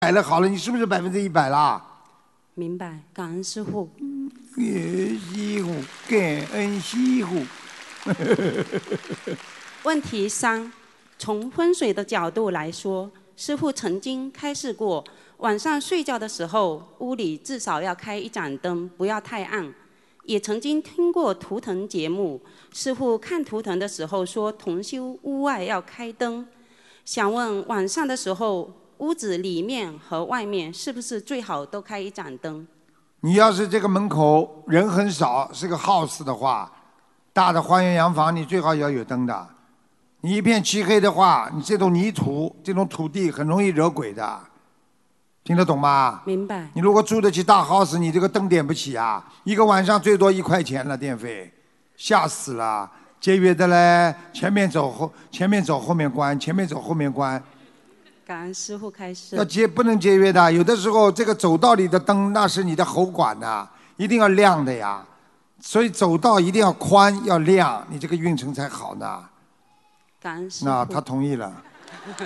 Pertanyaan murid di Seminar Dharma Sydney – 11 Januari 2020